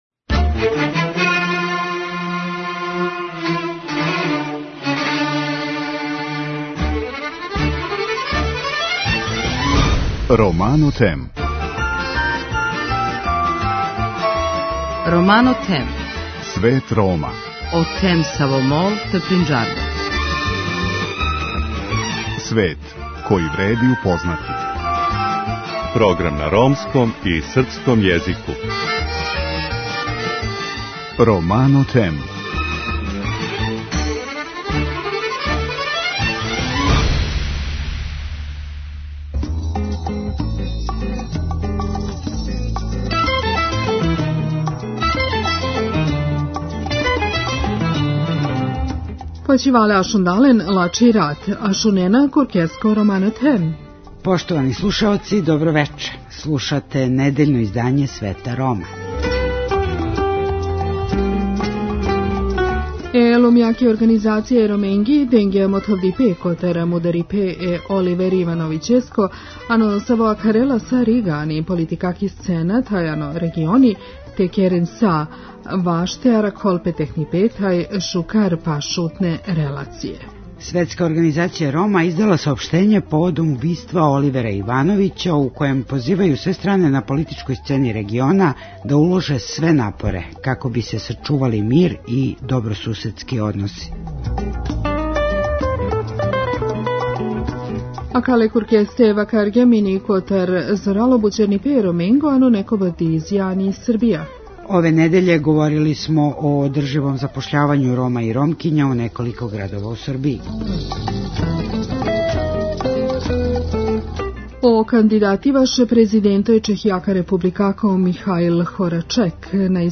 У Панорами догађаја који су обележили недељу за нама припремили смо: